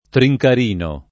trincarino [ tri j kar & no ] s. m. (mar.)